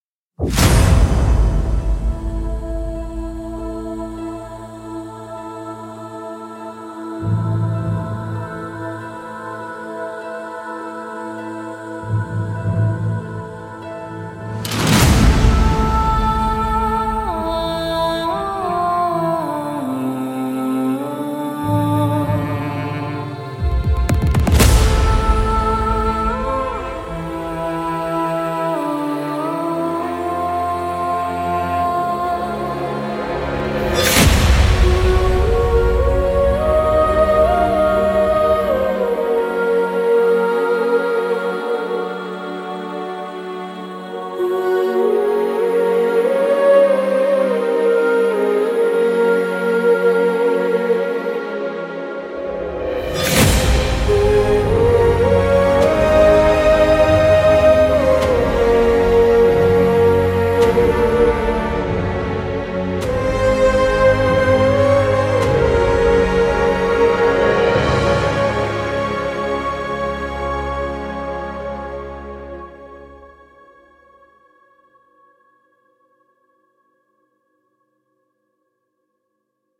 她丰富，敏捷和阴郁的人声特征毫不费力地融合了古典，流行，民间，布鲁斯，休闲和爵士的人声风格。
• 明亮和黑暗短语类别的节奏均为100bpm和140bpm
• 特殊的120bpm Hummed短语类别
• 人声表演可增强真实感